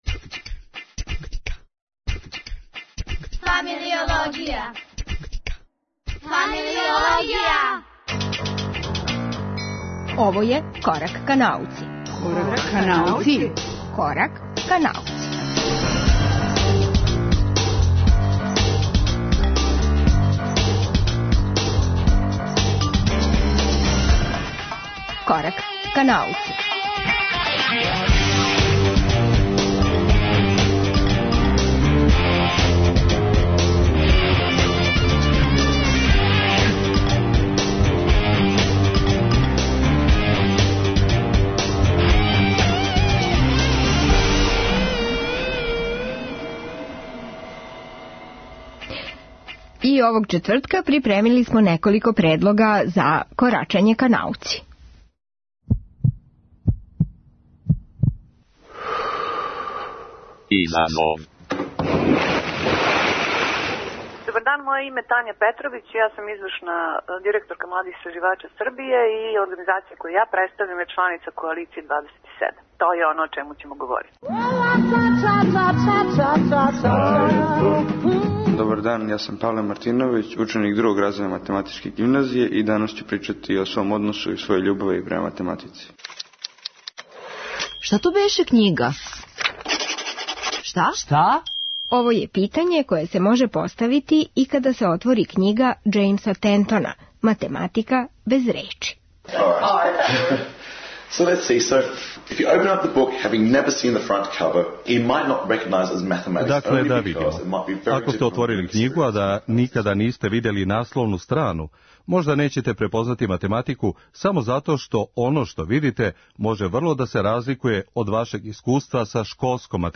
Шта то беше књига - разговор